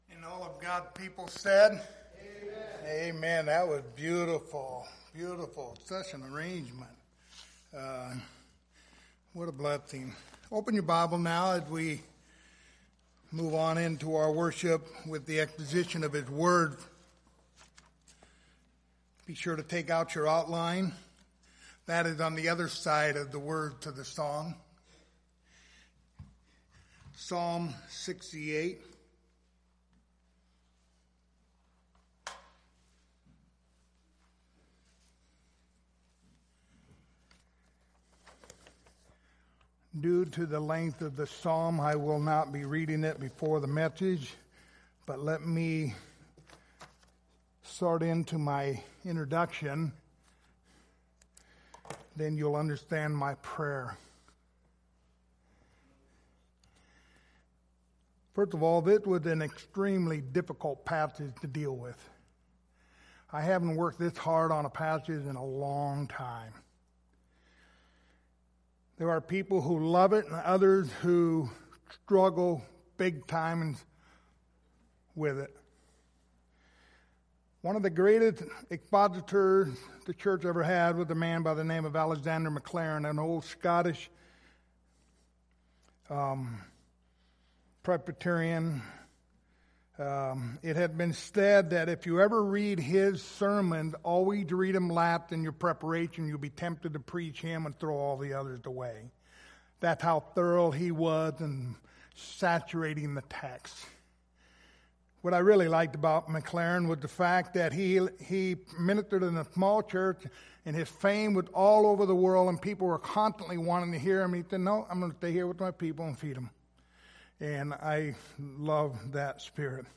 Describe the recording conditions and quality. The book of Psalms Passage: Psalm 68:1-35 Service Type: Sunday Morning Topics